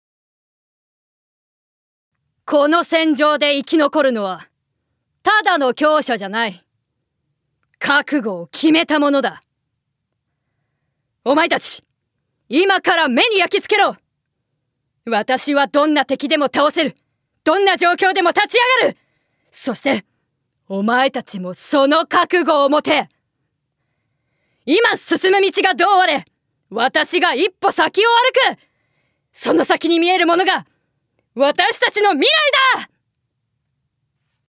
◆女騎士◆